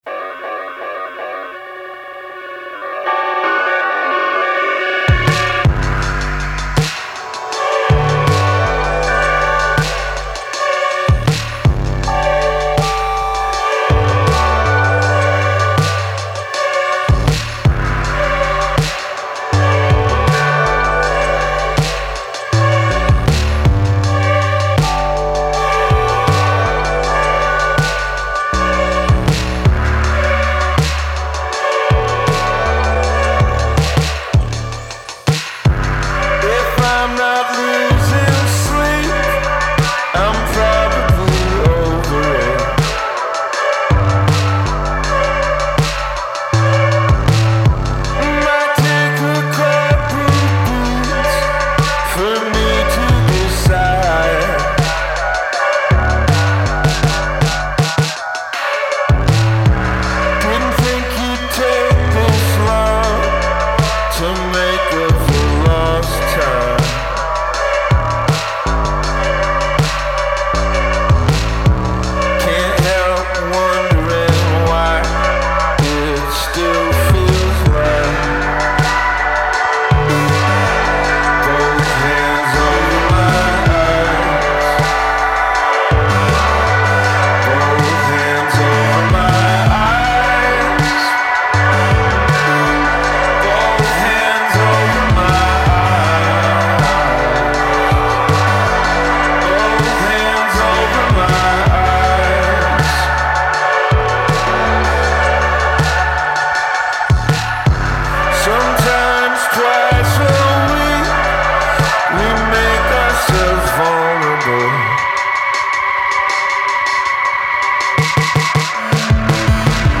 admitting to himself in his signature baritone